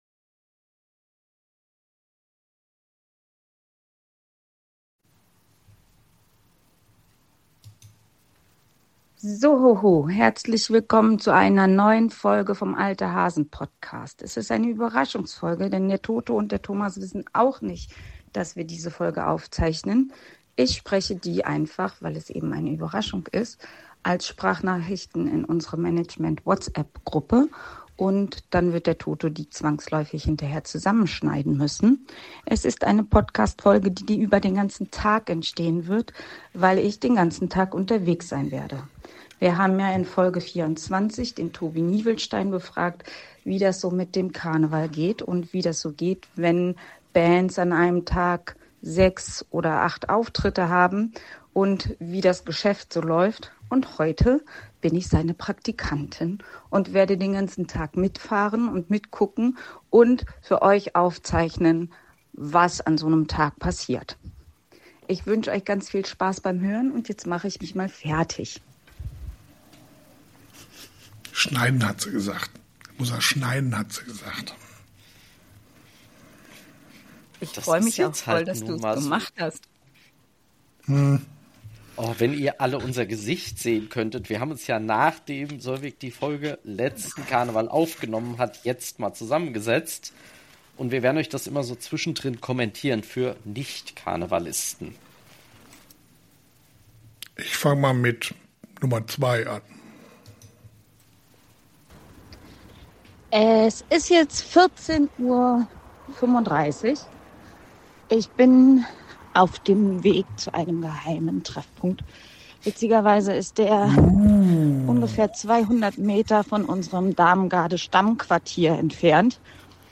Und am Ende kann man sogar an ihrer Stimme hören, wie anstrengend der Tag war.